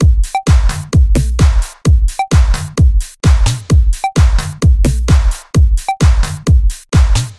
126 Bpm Classic Analog House
描述：Deep House drum loop created using Ableton Live 8.
标签： Loop Deep Drum House
声道立体声